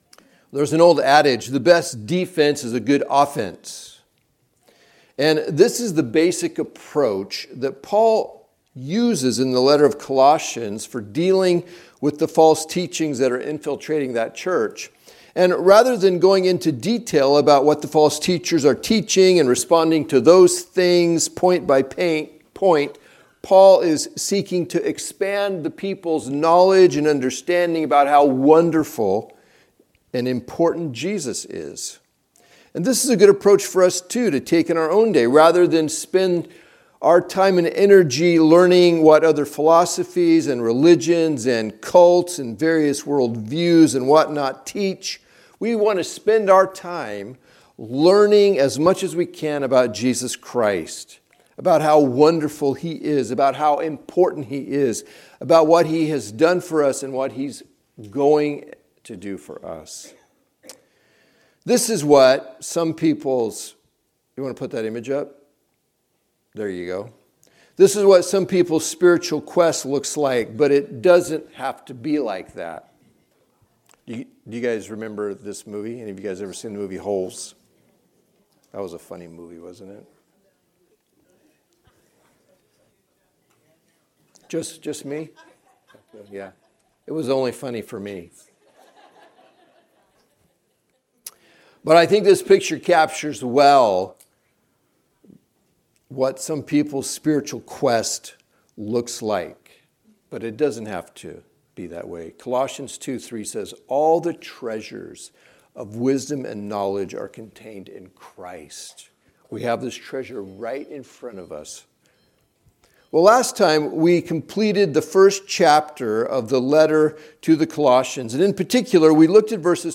Recordings of the teaching from the Sunday morning worship service at Touchstone Christian Fellowship. Tune in each week as we move through the Bible in a way that is both relevant and challenging.